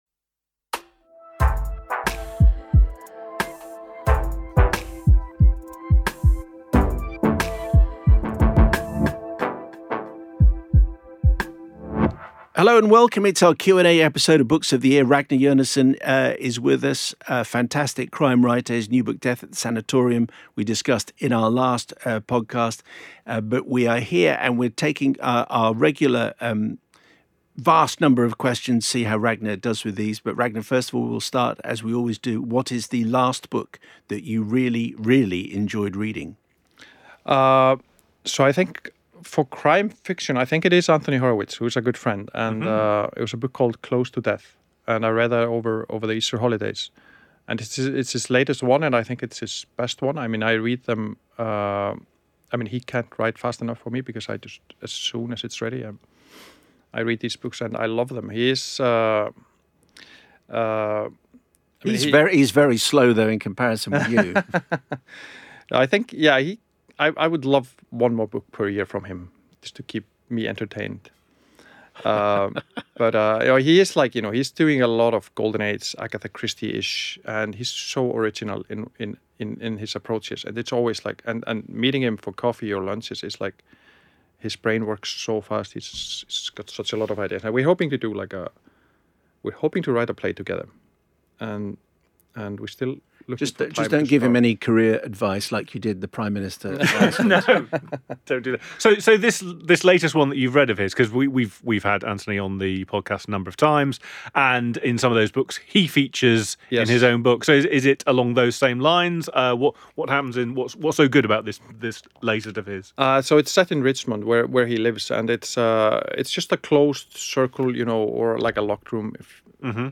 Ragnar Jonasson Q&A
We also hear from fellow author Vaseem Khan, who poses a question to Ragnar, and discover who he would invite to his fantasy dinner party.